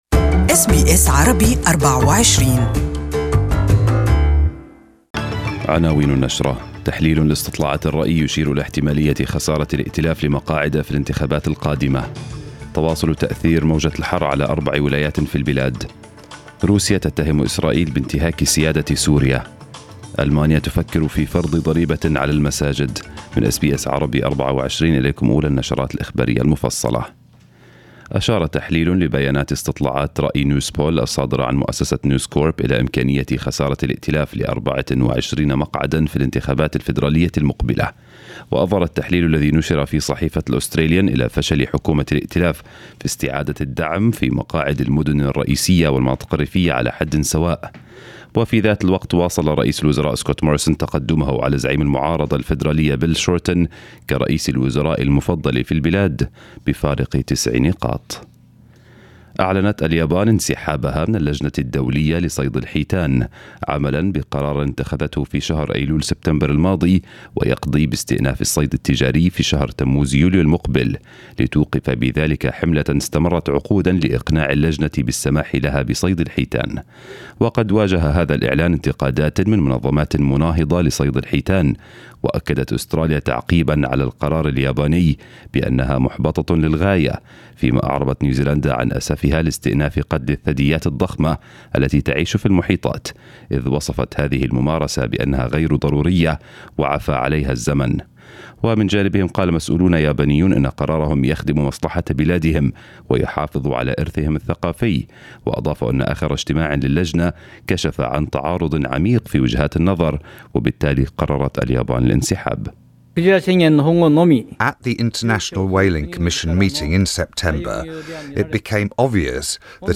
News bulletin in Arabic.